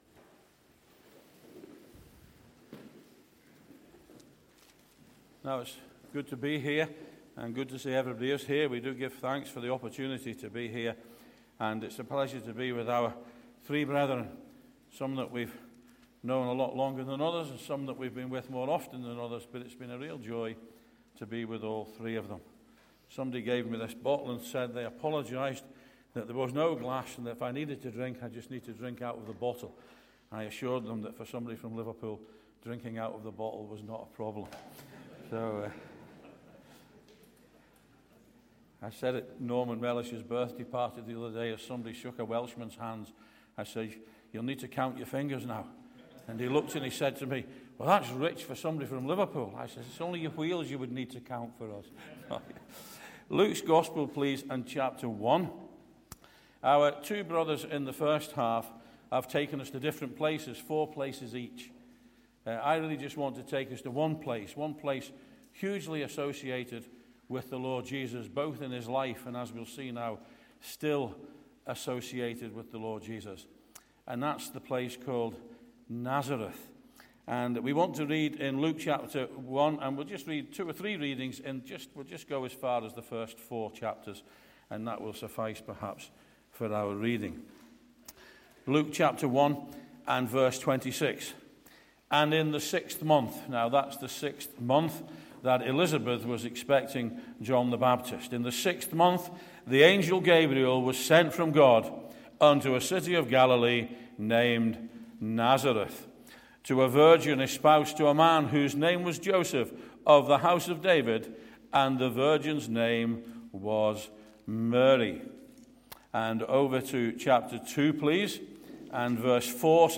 2025 Easter Conference